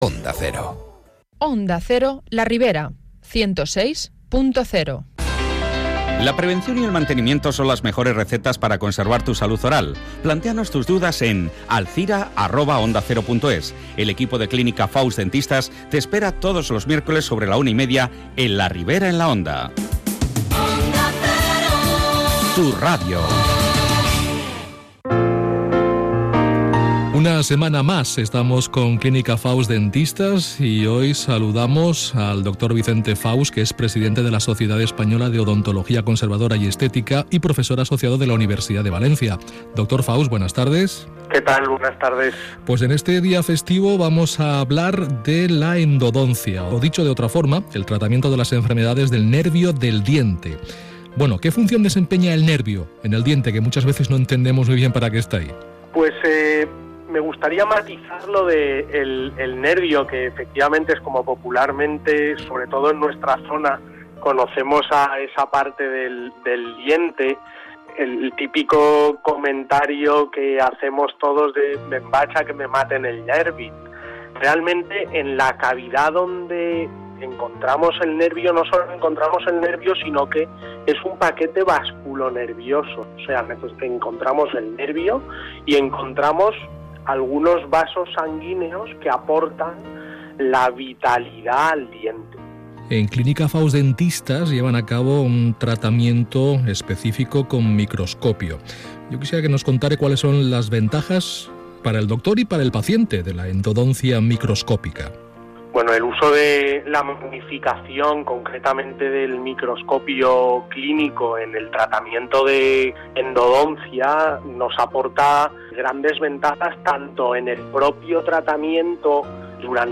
Entrevista en Onda Cero Alzira